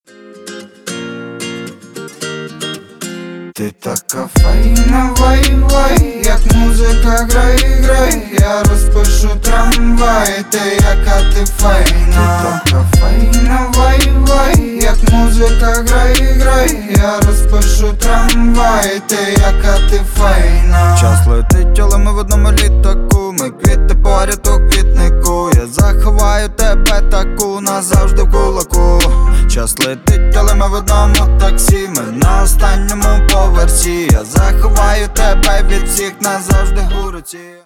• Качество: 320, Stereo
гитара
мужской голос
Хип-хоп
спокойные
Украинский хип-хоп под гитару